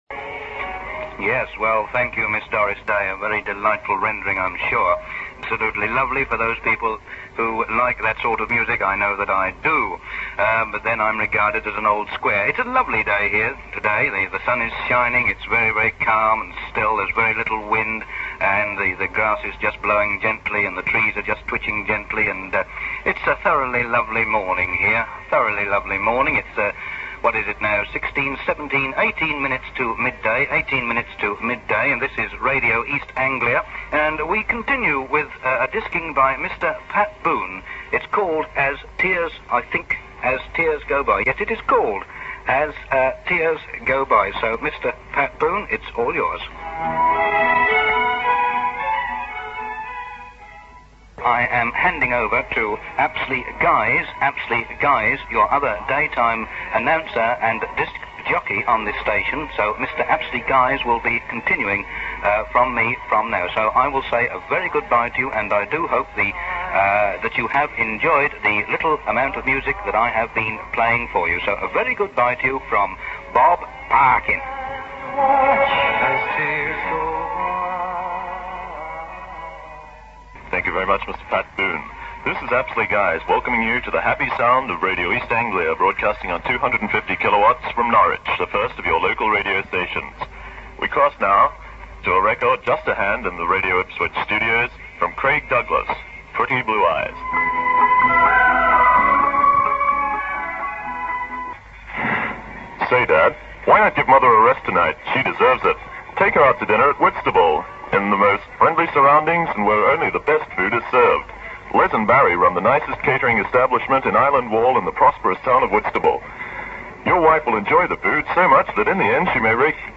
In keeping with the generally held view that no new station could be as exciting as the pirates, the Radio East Anglia programmes were particularly inept with discs ‘wowing’, the DJs misreading scripts and an uninspiring choice of music.
As the time approached 12 noon, the traditional time for April Fooling to end, Radio London cut through again.